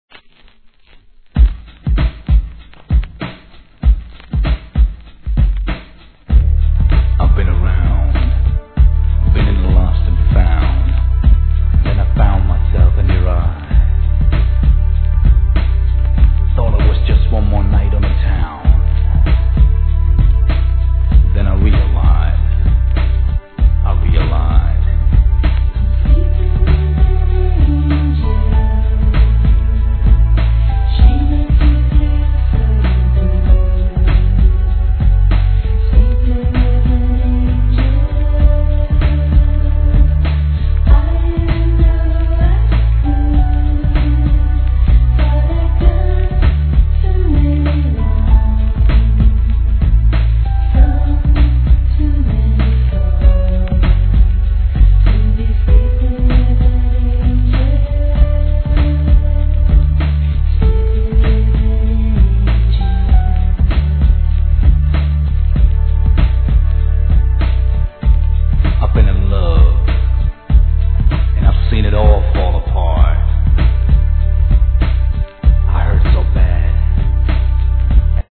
HIP HOP/R&B
1995年、グランド・ビート作!